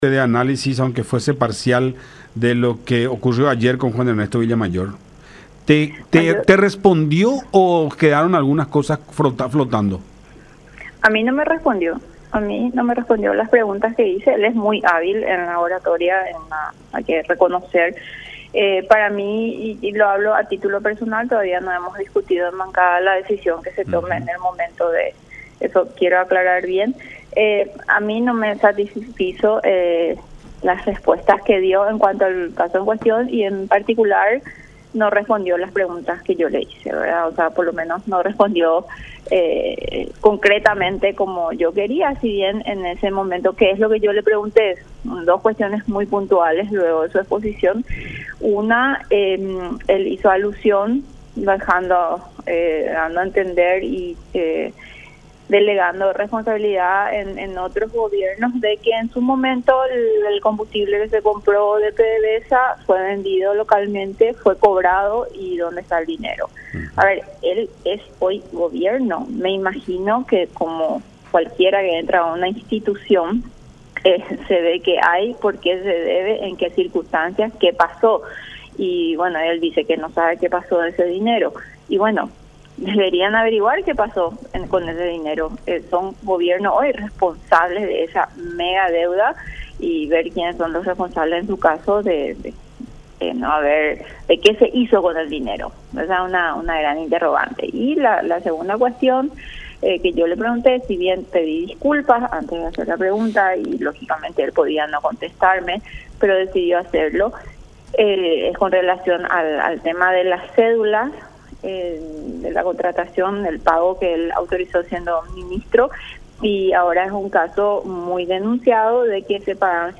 No contestó las dos preguntas que yo formulé”, cuestionó Vallejo en diálogo con La Unión.